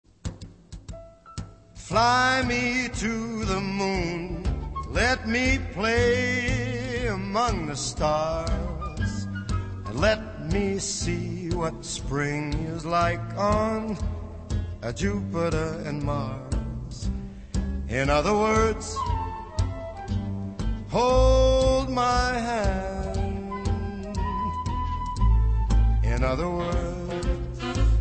• Jazz Ringtones